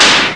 slap2.mp3